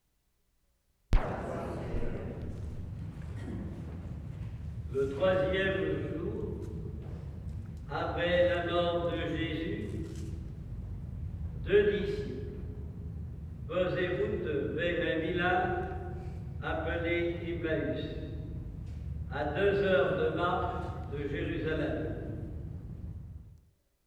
CATHOLIC MASS
3. (note 3-6, low rumble from heating system) Priest speaking; the two priests are miked, the amplification is hardly noticeable. When priest leaves podium, his voice hardly changes.
Church quite small, hardly any reverberation. When congregation gets up, chairs squeak against floor, no benches.